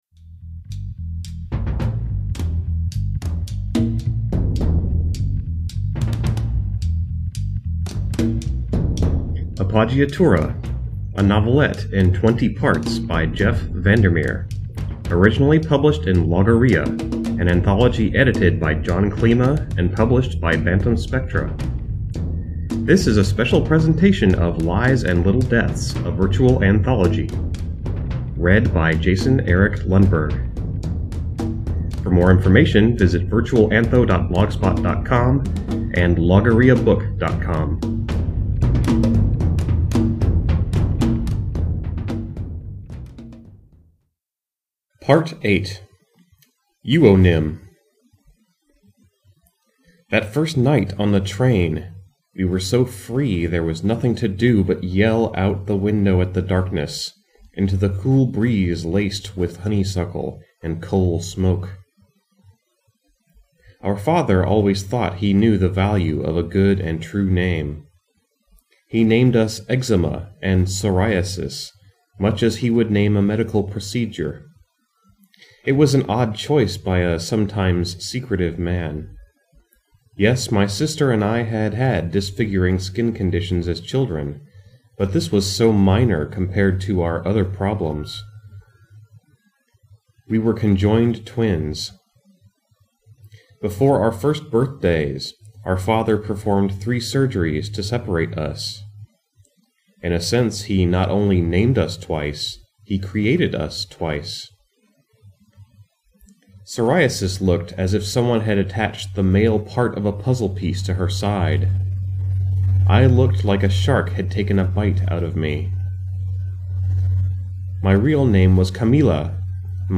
Music provided by The Church, and is used with permission.